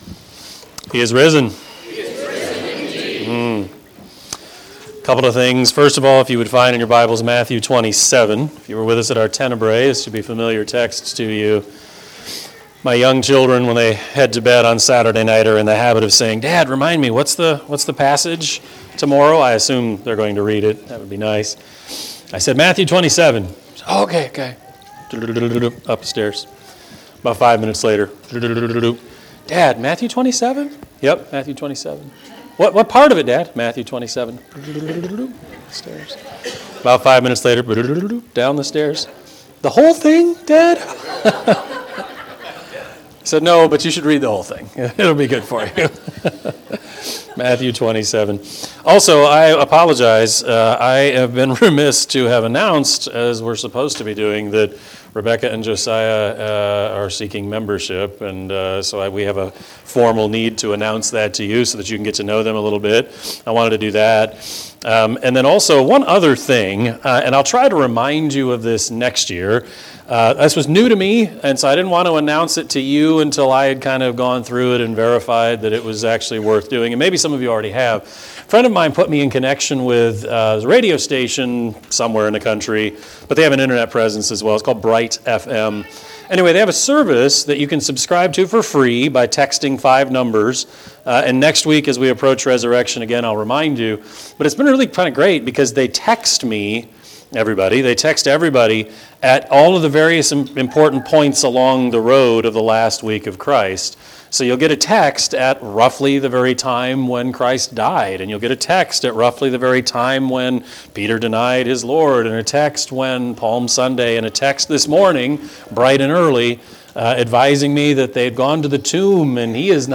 Sermon-4-9-23-Edit.mp3